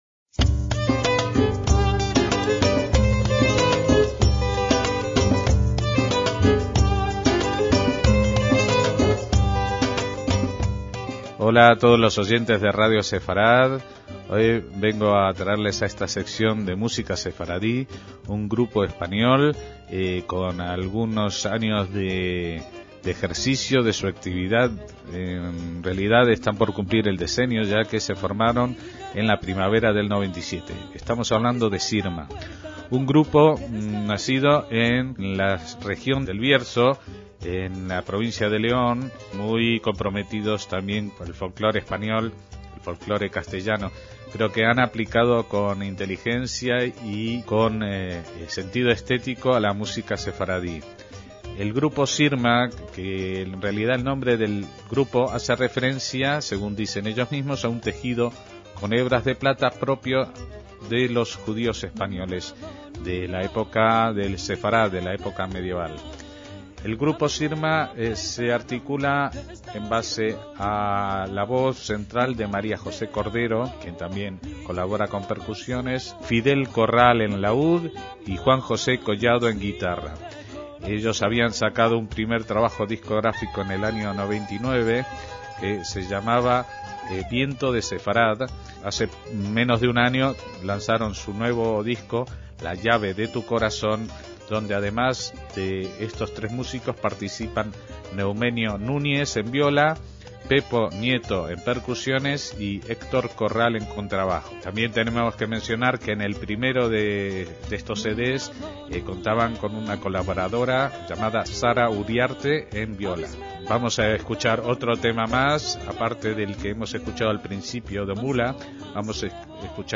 MÚSICA SEFARDÍ
voz y percusión
laúd
guitarra
viola